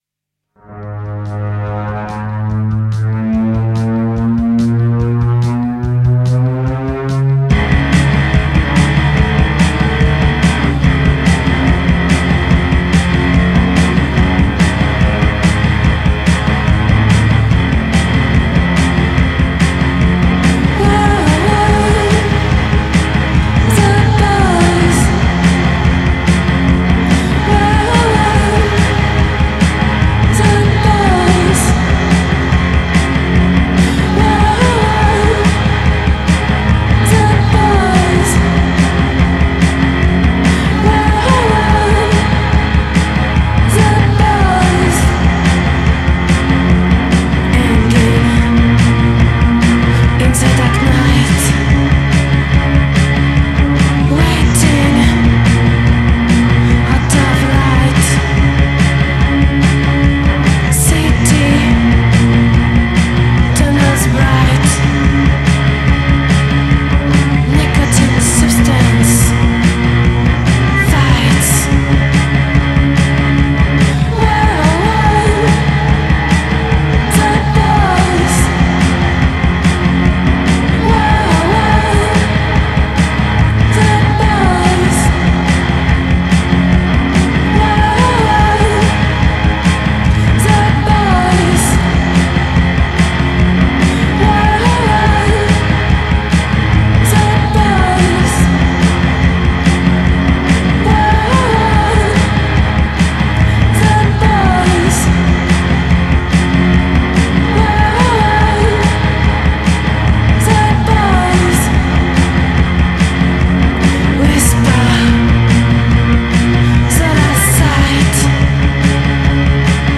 clavier
batterie